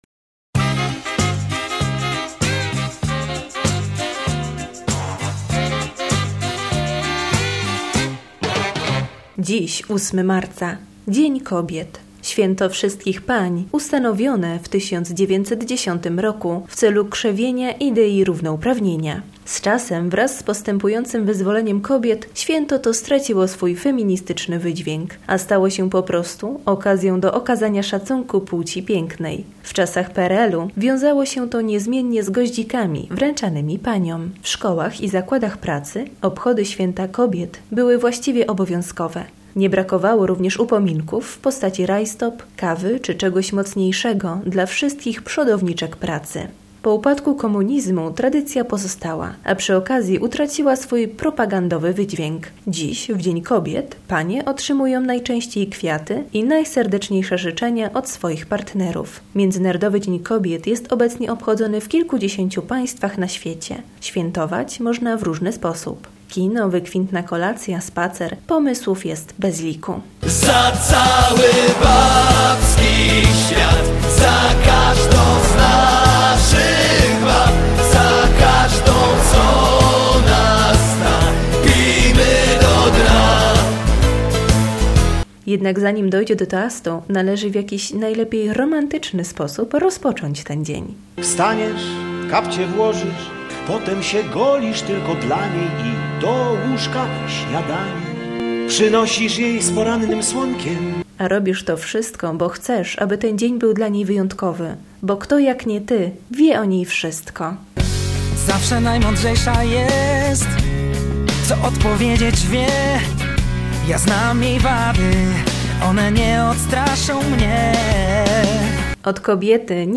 Wszystkim Paniom życzy cała męska załoga RADIA HIT